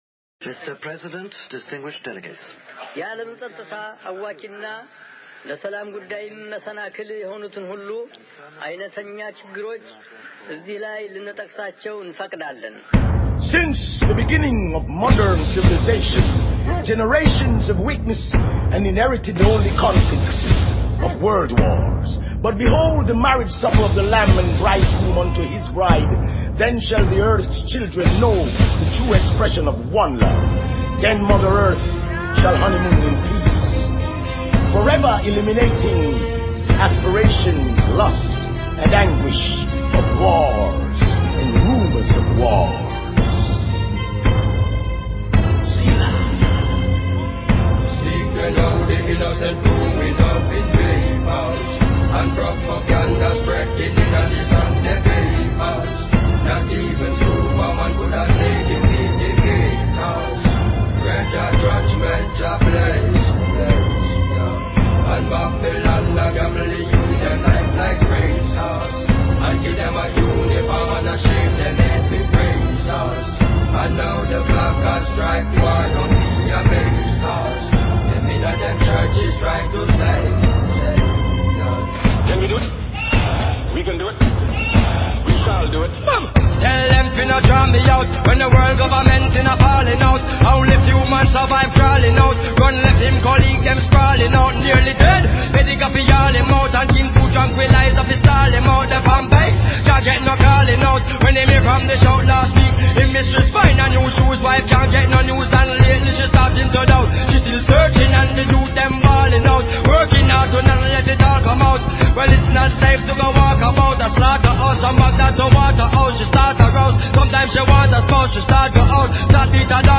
Talk Show Episode, Audio Podcast, When_Pigs_Fly and Courtesy of BBS Radio on , show guests , about , categorized as